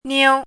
chinese-voice - 汉字语音库
niu1.mp3